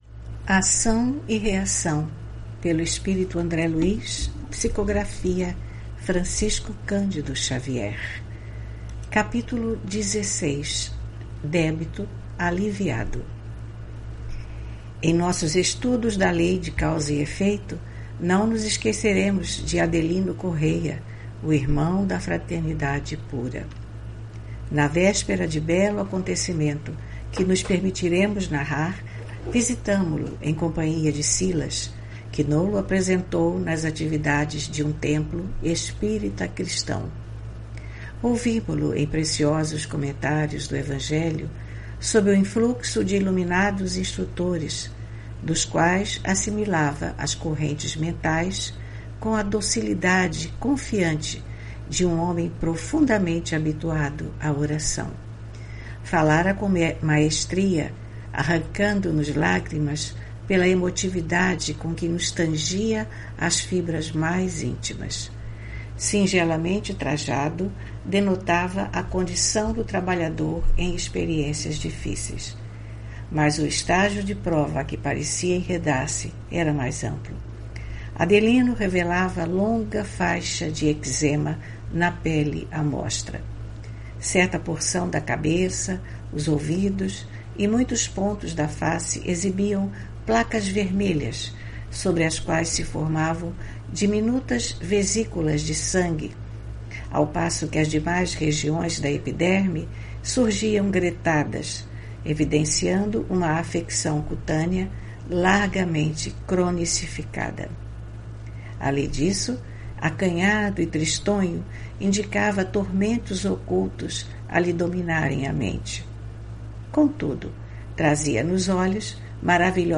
Leitura do livro: Ação e reação, autoria do espírito André Luiz, psicografia de Francisco Candido Xavier.